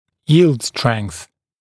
[jiːld streŋθ][йи:лд стрэнс]предел текучести (физ. предел силы, после которого происходит постоянная деформация металла)